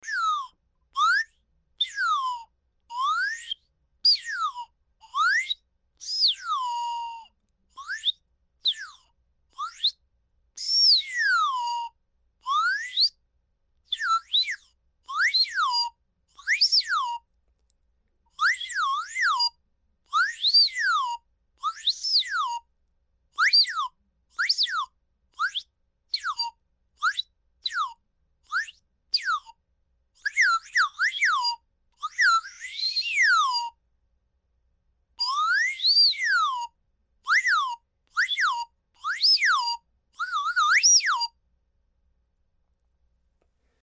スライドホイッスル
/ F｜演出・アニメ・心理 / F-18 ｜Move コミカルな動き
カートゥーン C414